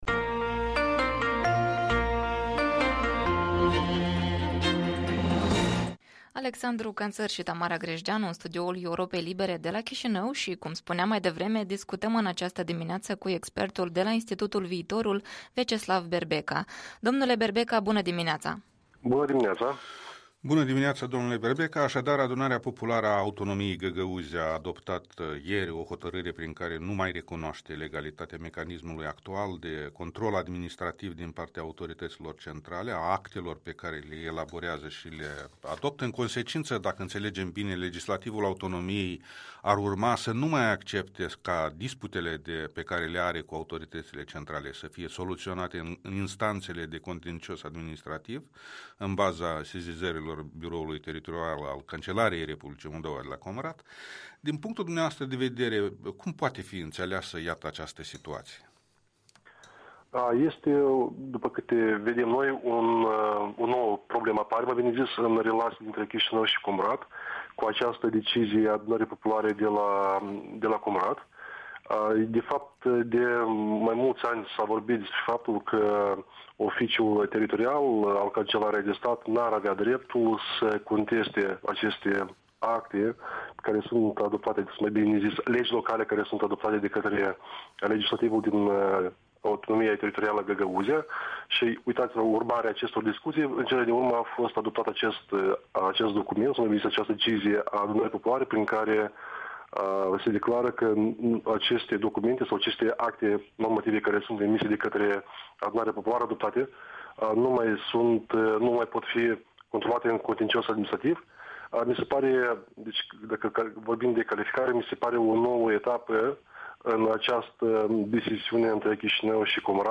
Interviul matinal la EL